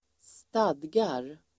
Uttal: [²st'ad:gar]